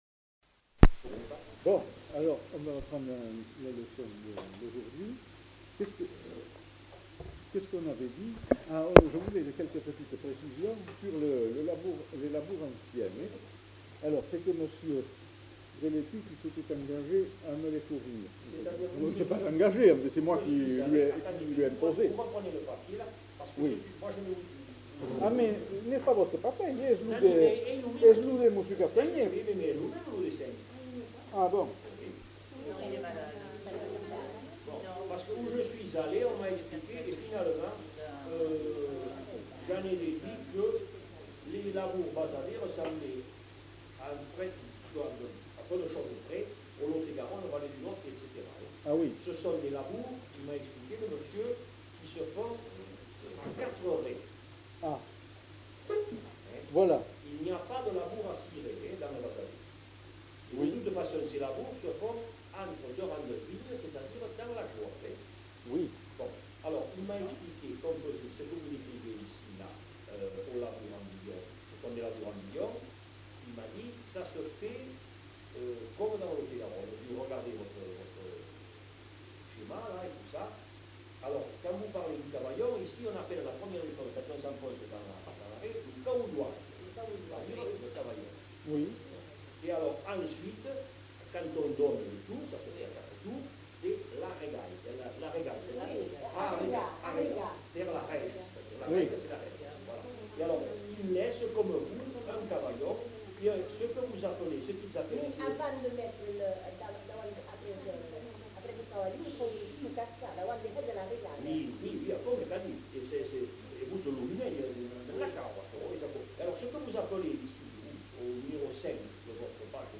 Lieu : Bazas
Genre : témoignage thématique